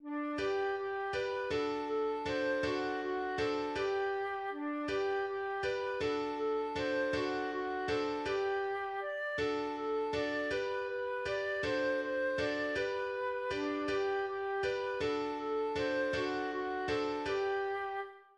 Lied eines Perainegottesdienstes